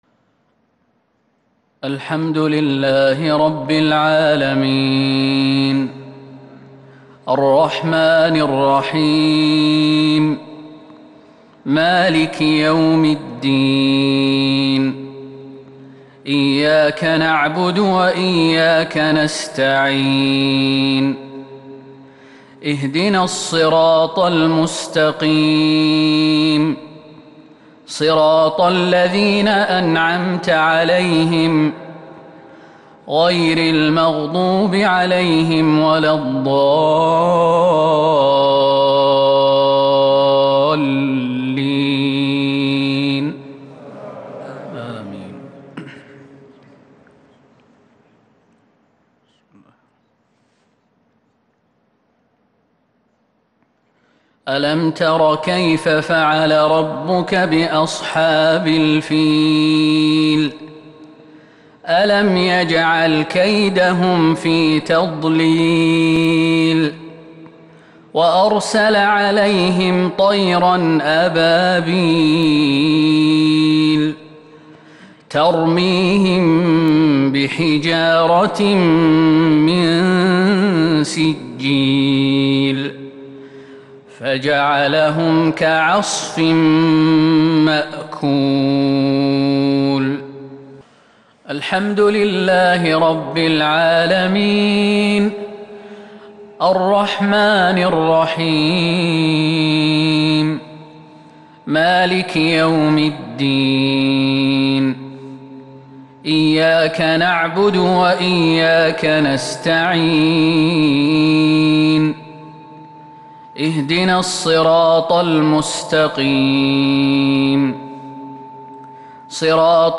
مغرب الاثنين 19 شوال 1442هــ سورتي الفيل وقريش | Maghrib prayer from the Surah Al-Fil and Quraysh 31/5/2021 > 1442 🕌 > الفروض - تلاوات الحرمين